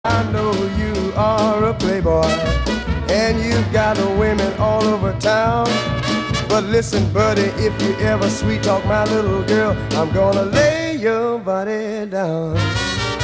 джаз и блюз: